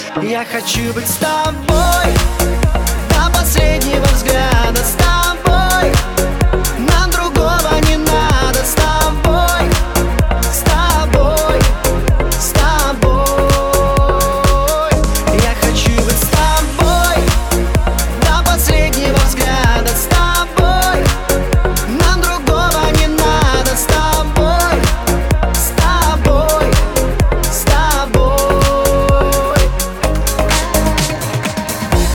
• Качество: 320, Stereo
поп
dance
Танцевальный ремикс